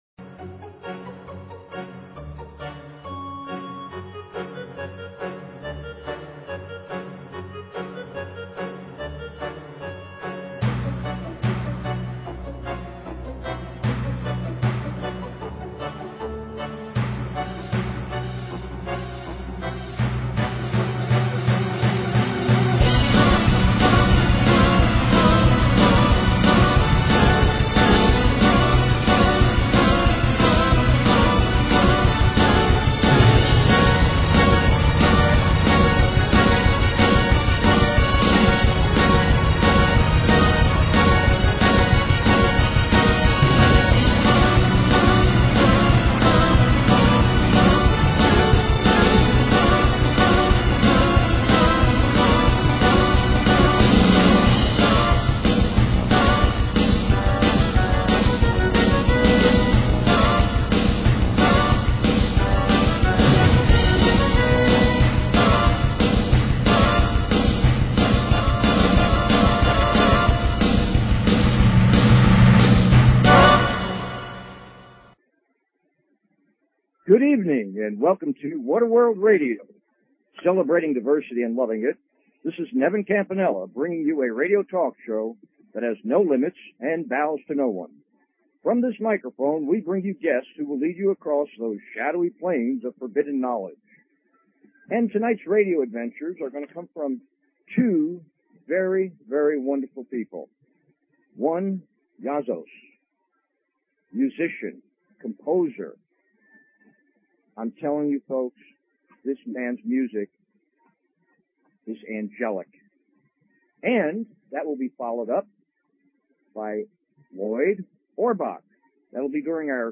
Talk Show Episode, Audio Podcast, What_A_World and Courtesy of BBS Radio on , show guests , about , categorized as